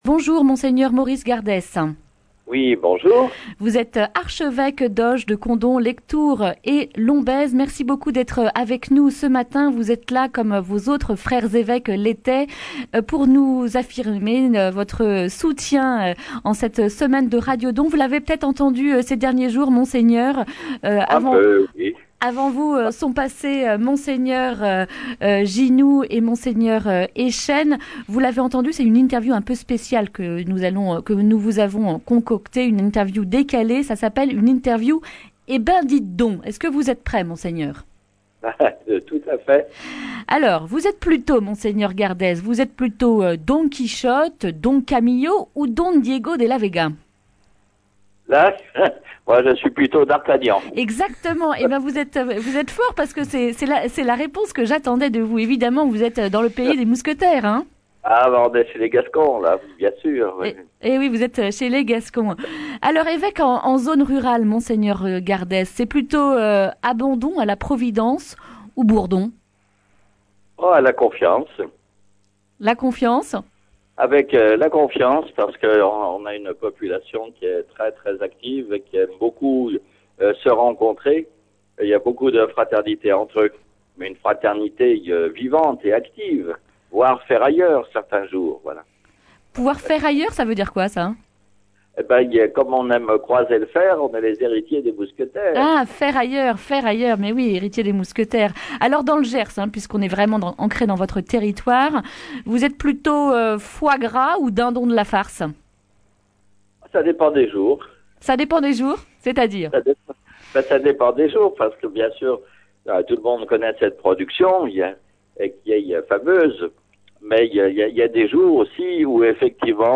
jeudi 21 novembre 2019 Le grand entretien Durée 11 min
Comme ses frères évêques en début de semaine, c’est au tour de l’archevêque d’Auch, Condom, Lectoure et Lombez de se prêter à nos interview spécial « radio don »